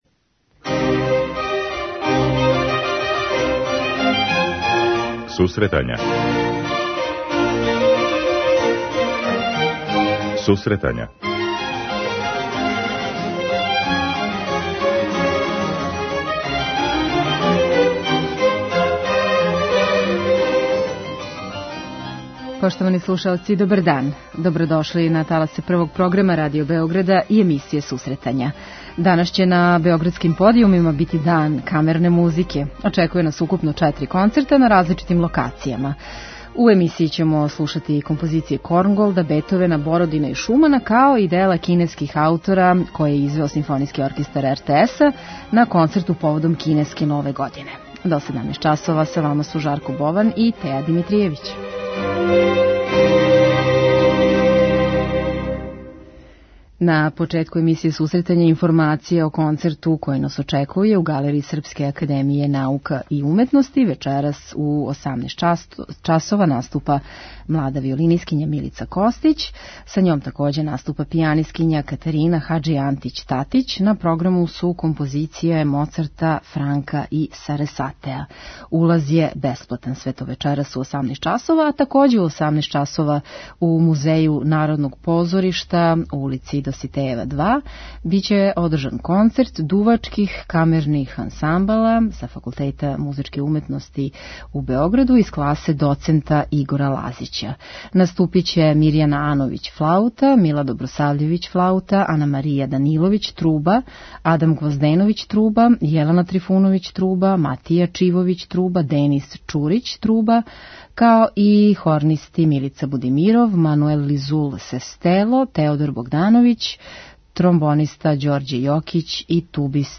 У емисији Сусретања слушаћемо и одабране композиције Корнголда, Бетовена, Бородина и Шумана а сам крај емисије употпунићемо делима кинеских аутора које је извео Симфонијски оркестар РТС на концерту поводом кинеске Нове године.